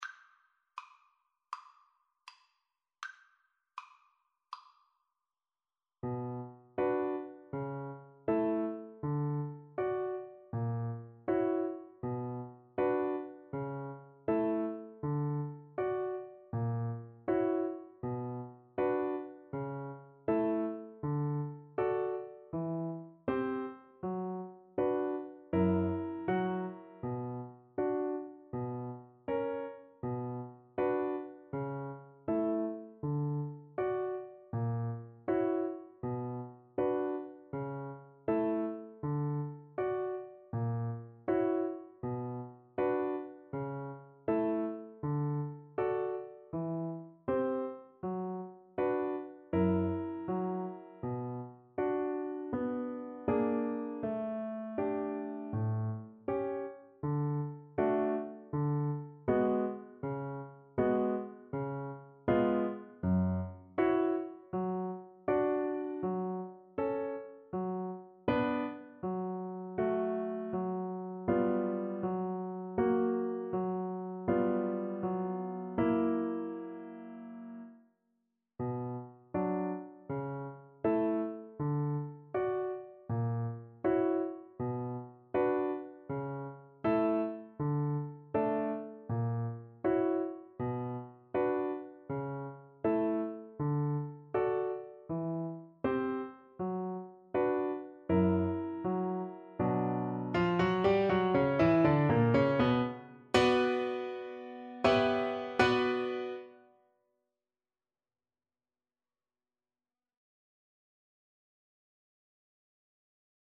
Play (or use space bar on your keyboard) Pause Music Playalong - Piano Accompaniment Playalong Band Accompaniment not yet available reset tempo print settings full screen
A minor (Sounding Pitch) (View more A minor Music for Bassoon )
Andante = c.80
Classical (View more Classical Bassoon Music)